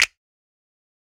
Snap.wav